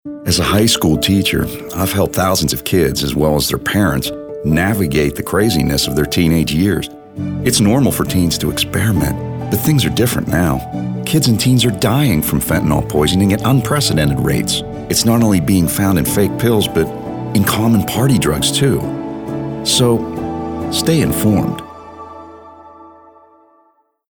caring, compelling, conversational, father, genuine, middle-age, real, serious, sincere, thoughtful, warm